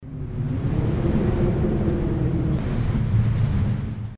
ambientWIND-1.mp3